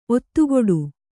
♪ ottugoḍu